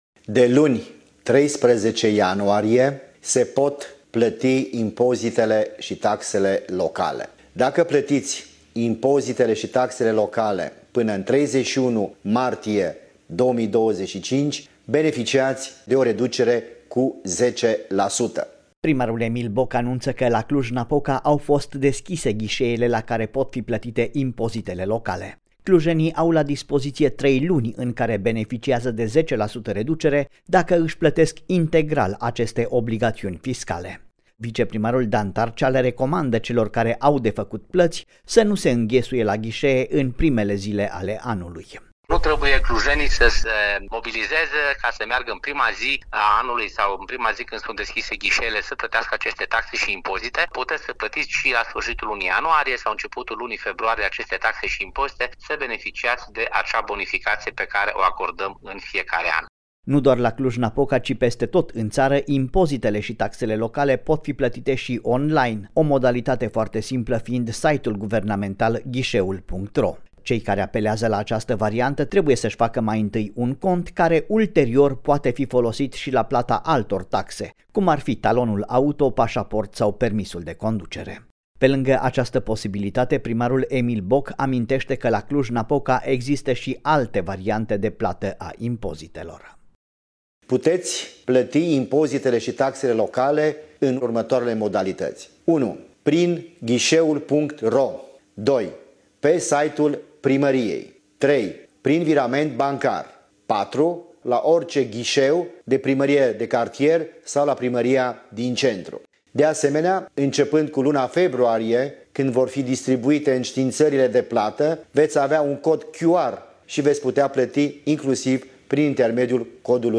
reportaj-plata-impozite-locale-2025.mp3